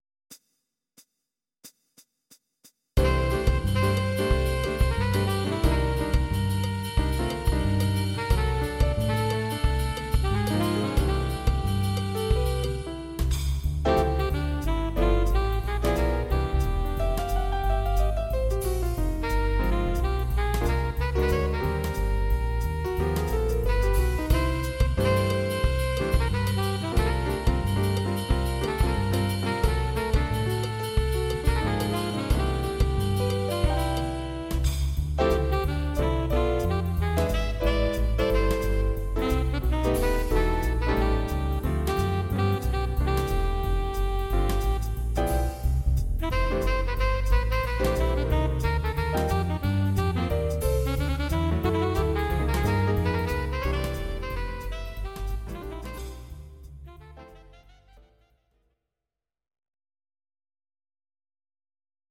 Audio Recordings based on Midi-files
Oldies, Musical/Film/TV, Jazz/Big Band